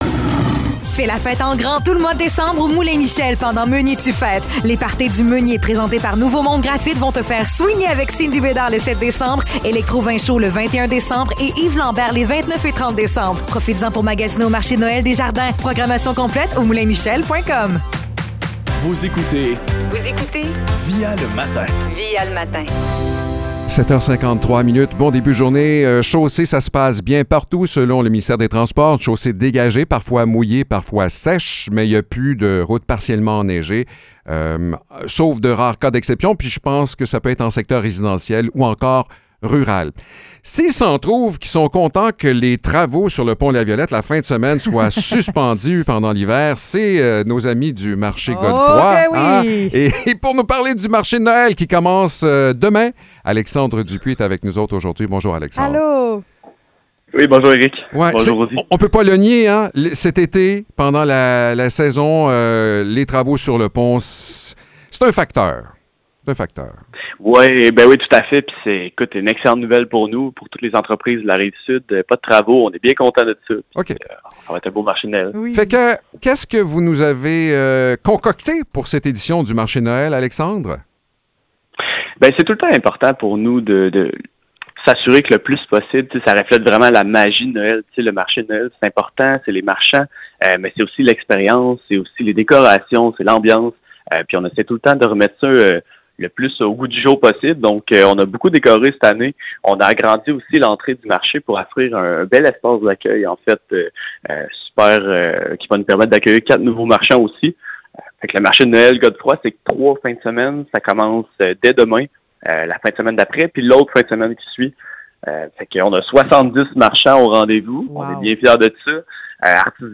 Entrevue pour le Marché de Noël du Marché Godefroy